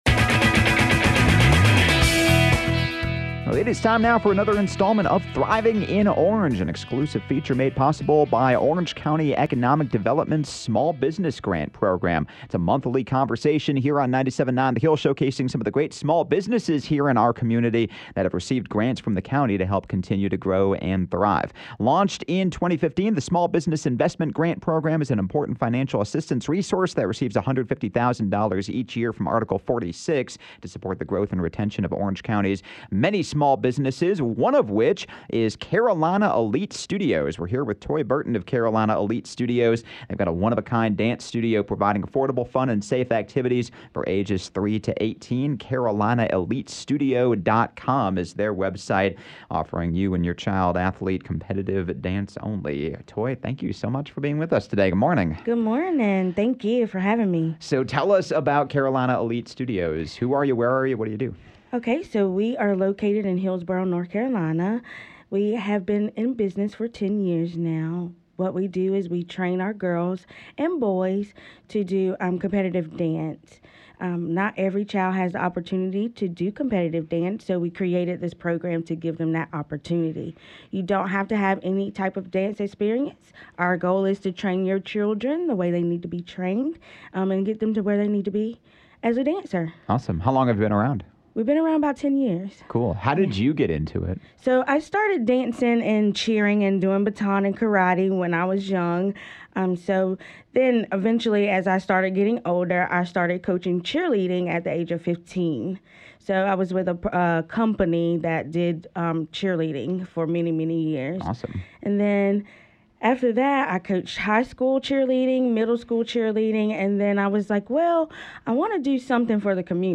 You can find more conversations like this in the “Thriving in Orange” archive on Chapelboro, and each month in a special segment airing on 97.9 The Hill!